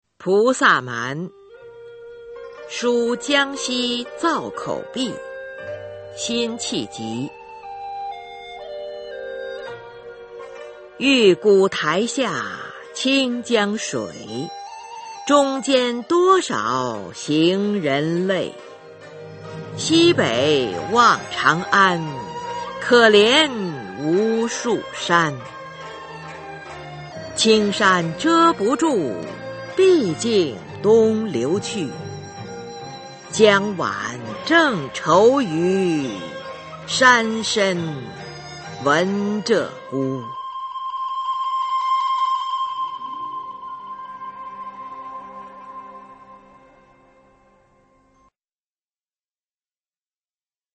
辛弃疾《菩萨蛮·书江西造口壁》原文和译文（含赏析、朗读）